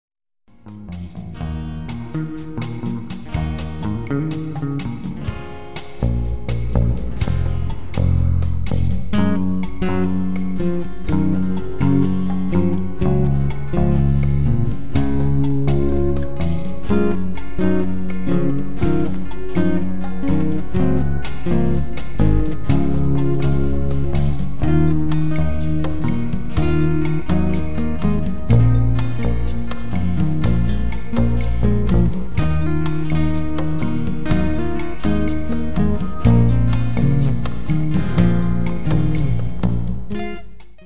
Sie sind zum träumen und entspannen gedacht.